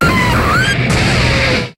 Cri de Heatran dans Pokémon HOME.